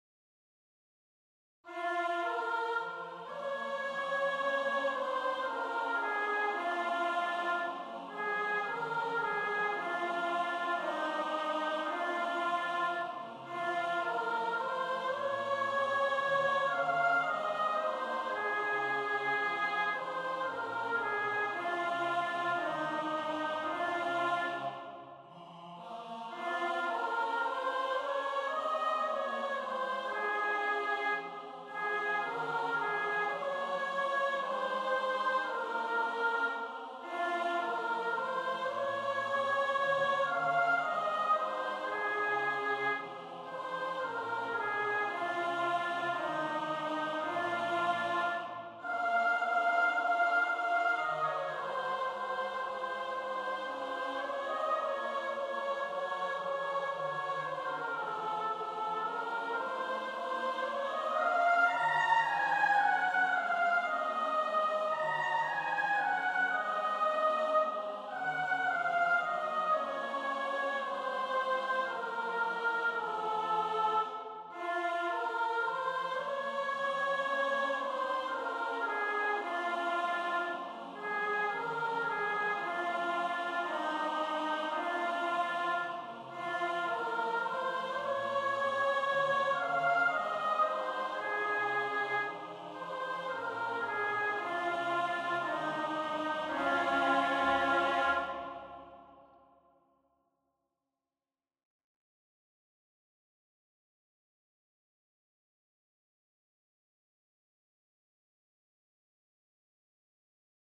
GAUDETE PRACTICE TRACKS:
4020-soprano.mp3